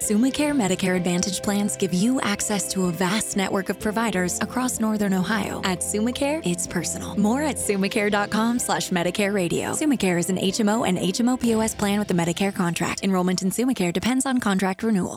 Network Radio Ad